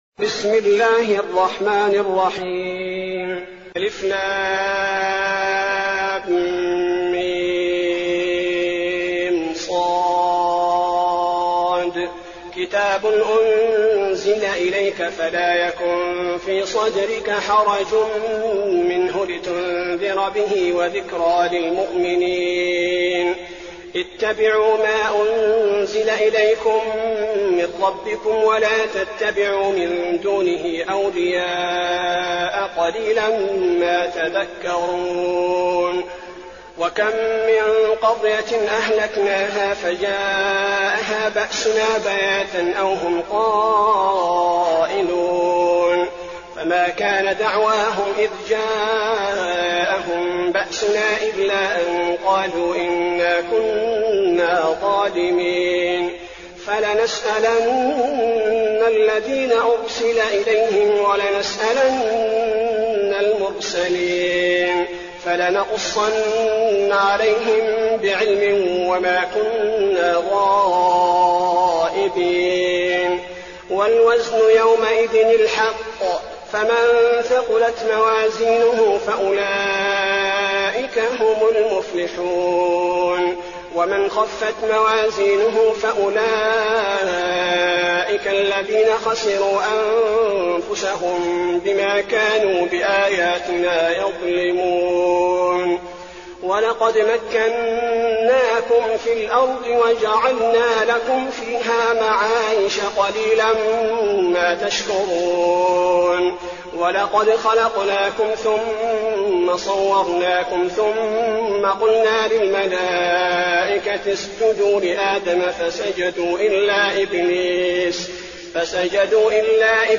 المكان: المسجد النبوي الأعراف